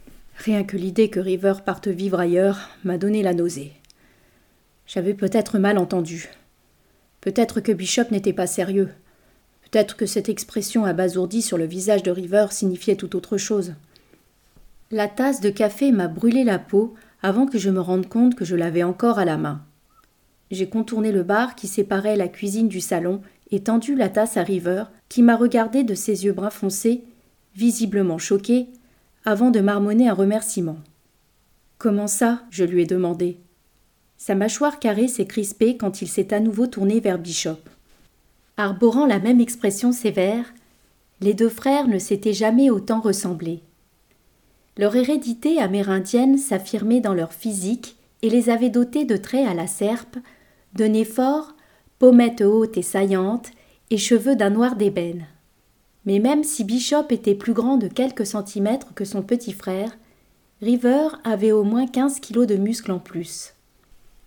Démo livre audio romance